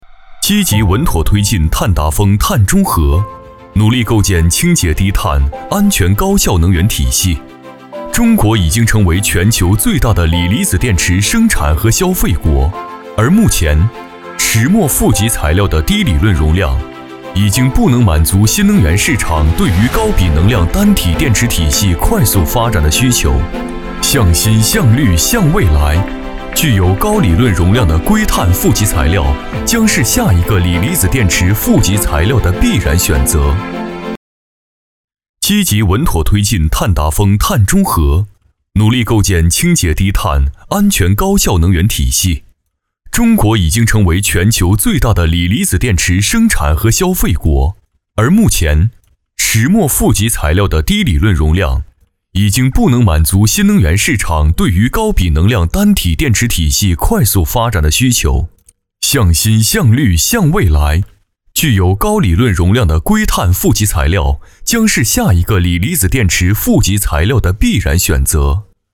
国语配音
男654-企业专题---科技驱动未来-创新赋能能源.mp3